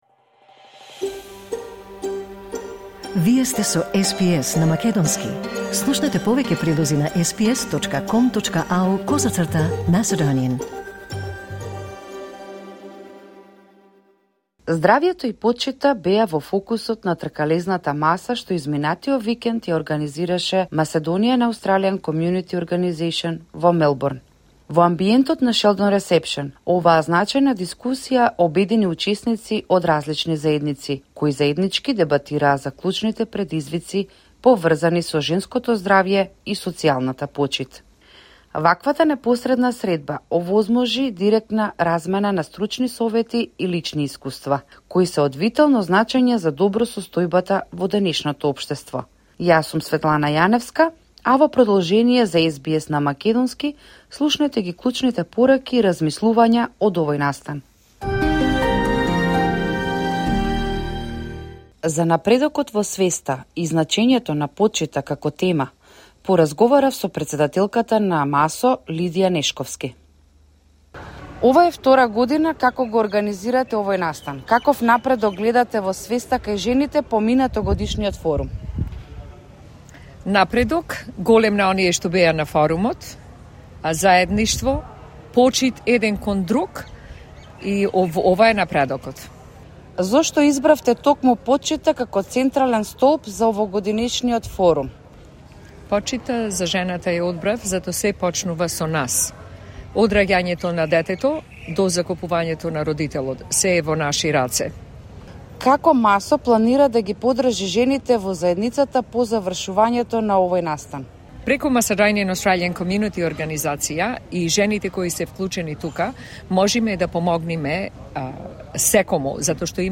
Значајна и инспиративна дискусија, одржана во пријатниот амбиент на „Шелдон Ресепшн“ во Мелбурн, обедини учесници од различни заедници, во заедничка дебата за клучните предизвици, поврзани со женското здравје и социјалната почит.